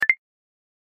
Spotify Feedback Sound Effect Free Download